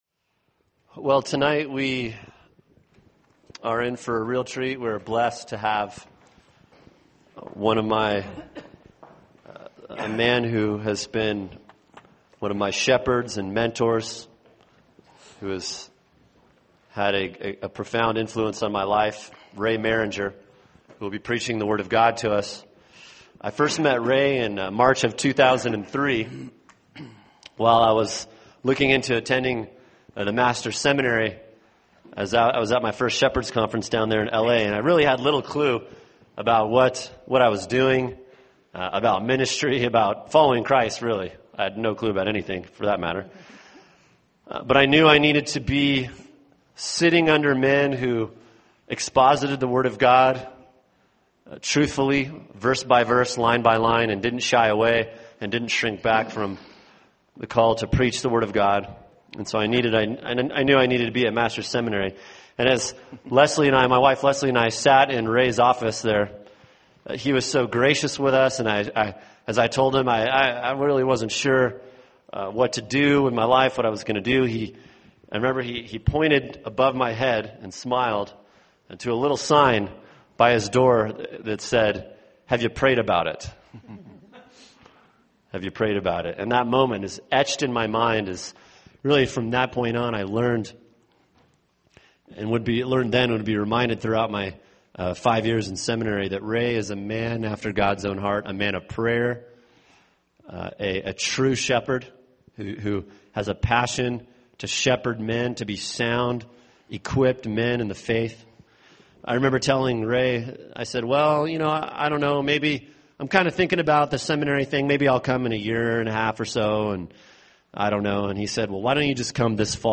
[sermon] various passages – “The Lost Disciplines” | Cornerstone Church - Jackson Hole